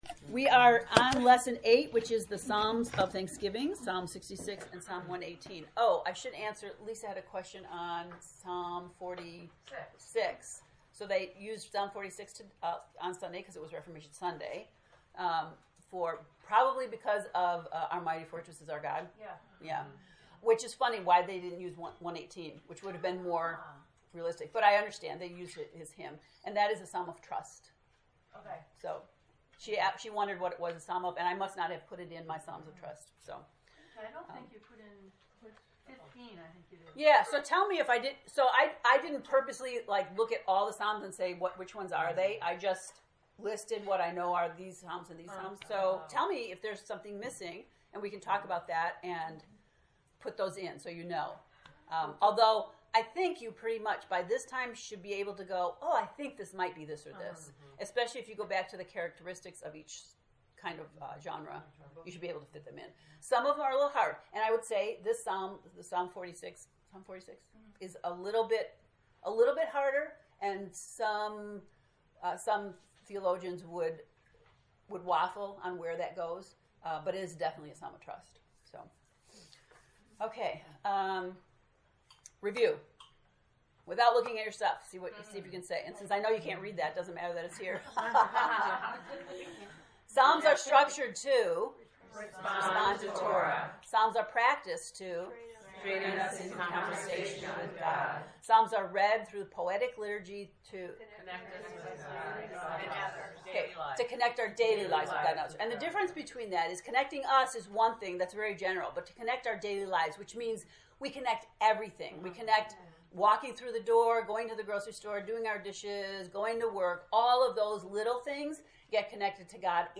To listen to the lesson 8 lecture, “Psalms of Thanksgiving,” click below: